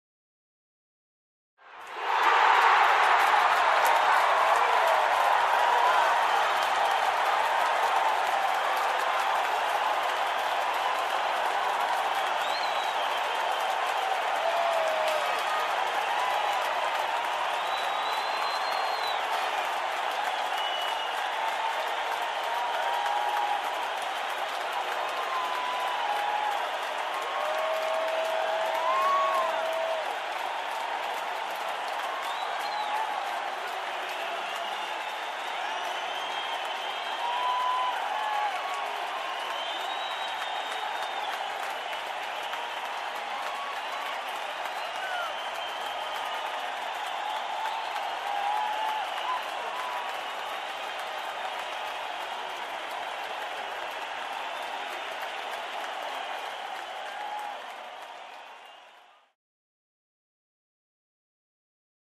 Крики на трибунах болельщиков после забитого гола